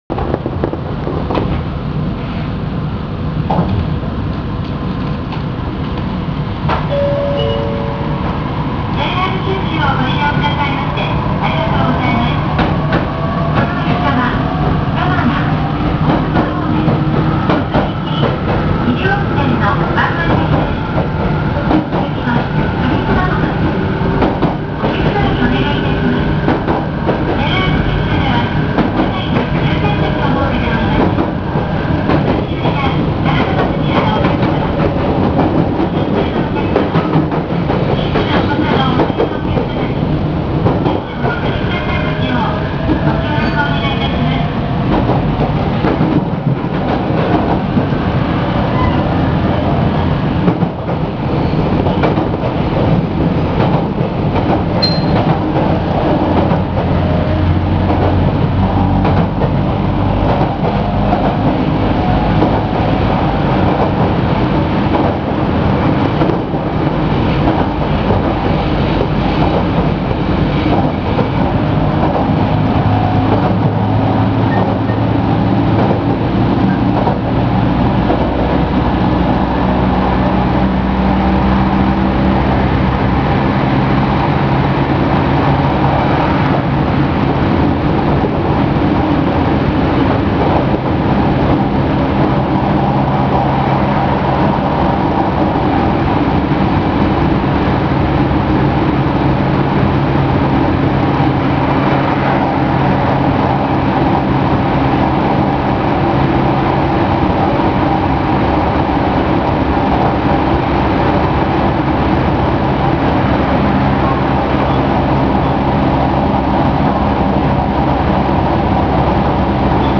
〜車両の音〜
・817系走行音
モーターは日立IGBTで、走行音自体は特に特徴のあるものではありません。ﾜﾝﾏﾝ運転の際には自動放送も流れますが、ツーマン時にも流れるのかは不明。